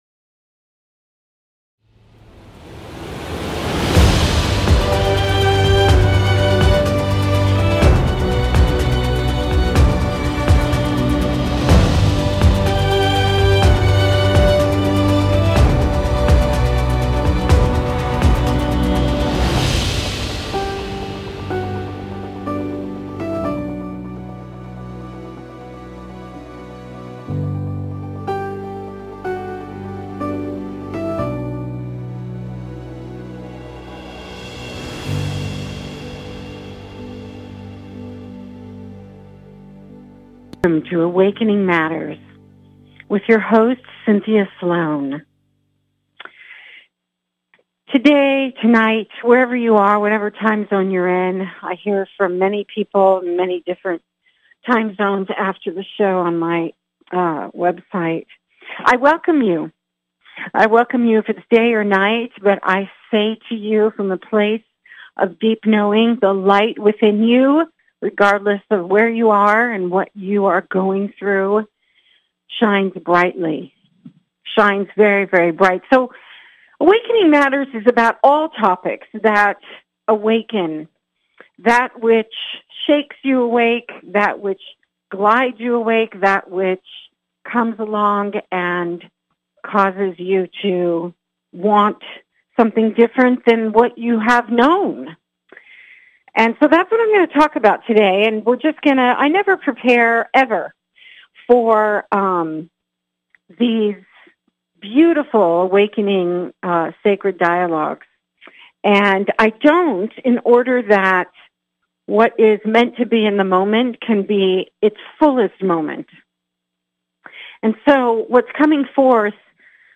A spiritual dialogue that invites divine wisdom, joy and laughter.